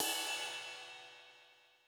RX5 RIDE 2.wav